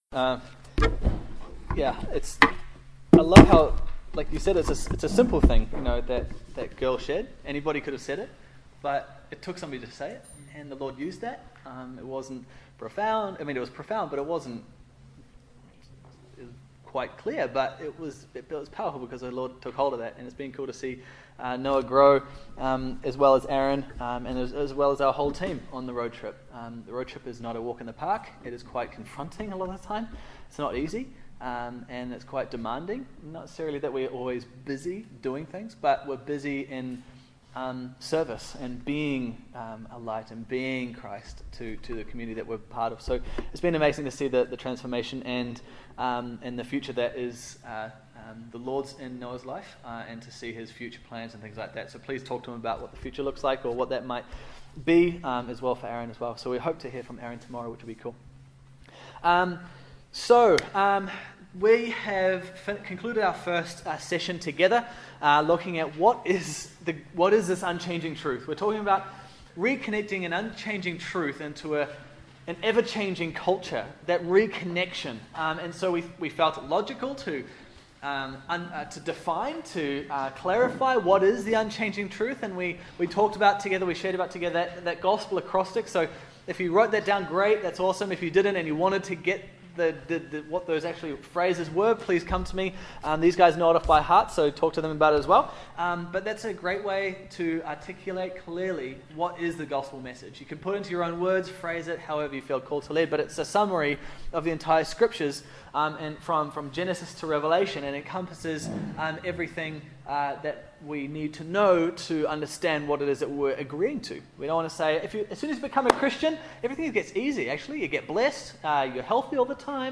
Church Camp Session 2 – Reconnecting Unchanging Truth to an Ever Changing Culture